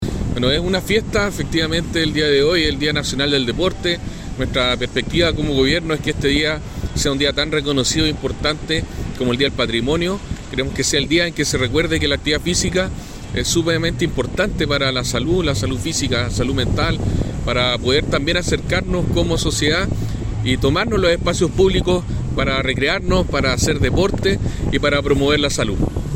DIA-DEL-DEPORTE-Delegado-Galo-Luna.mp3